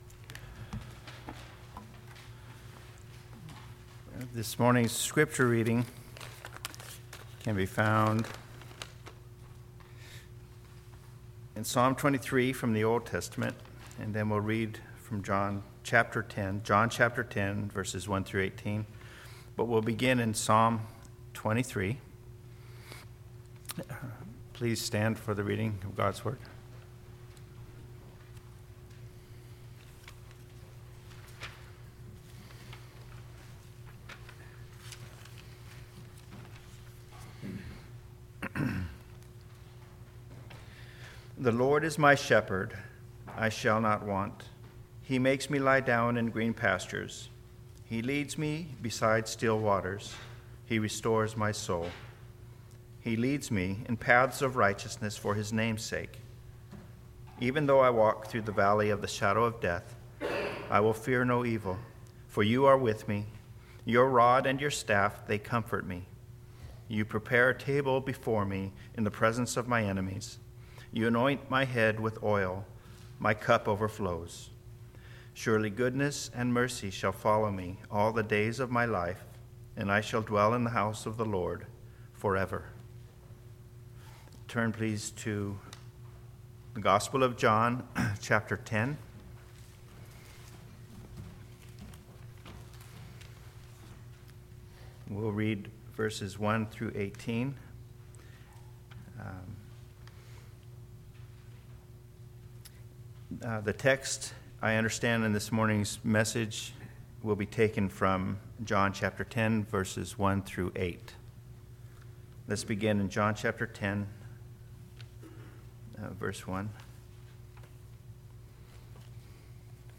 Silicon Valley Reformed Baptist Church in Sunnyvale, California
Curious about something taught in this sermon?